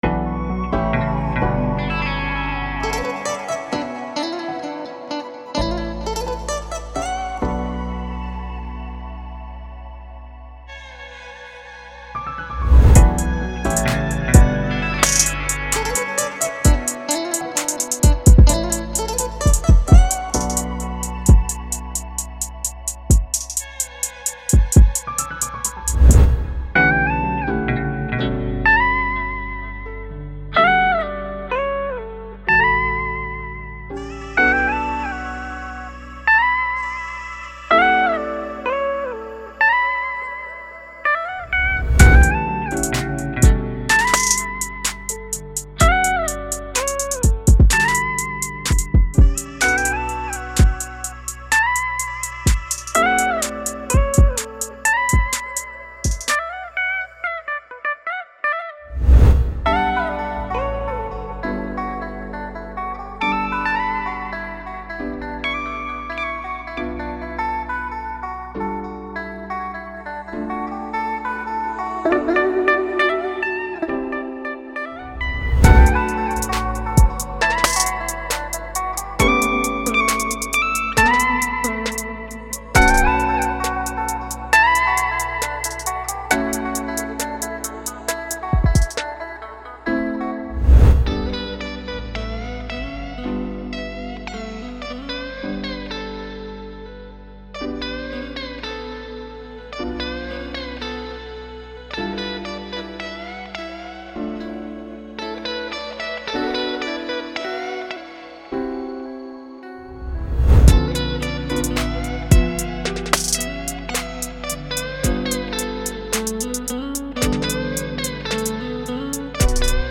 所有音频文件均经过专业混音和母带处理。